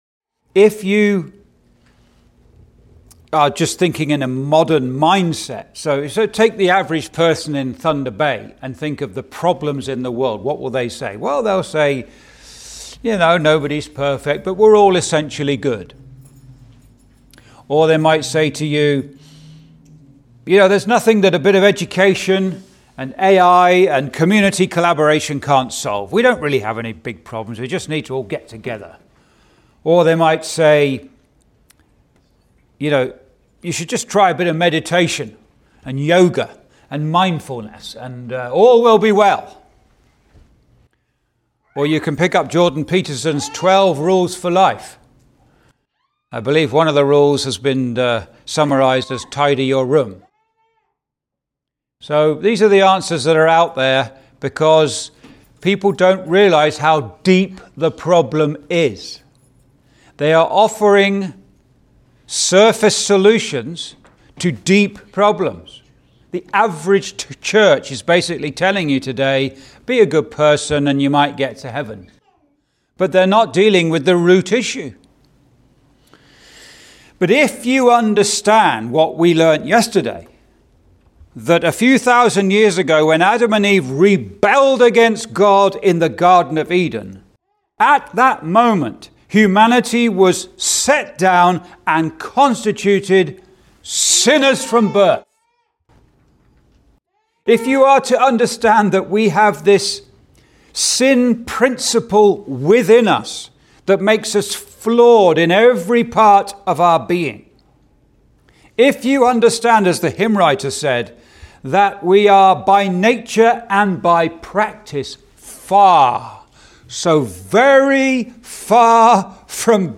(Recorded in Thunder Bay Gospel Hall, ON, Canada on 11th Nov 2025) Complete series on Great Bible Chapter 3's: The Great Tragedy -